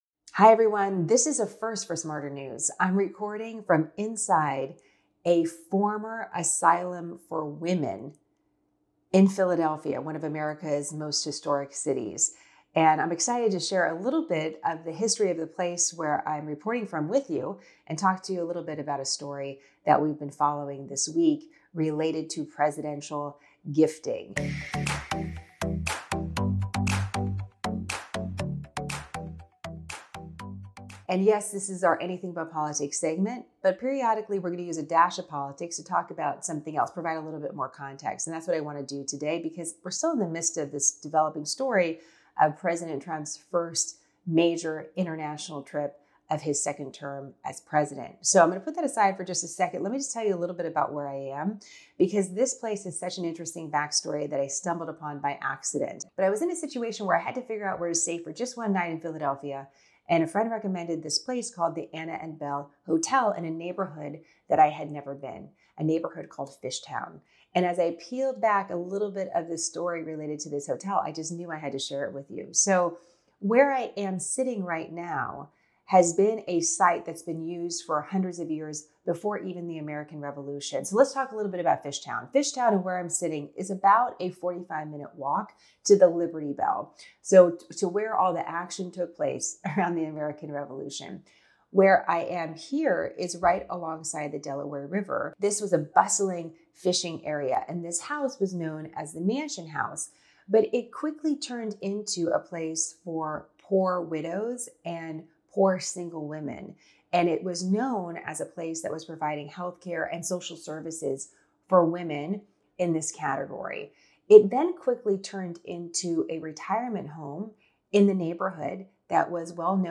*On location in Philadelphia*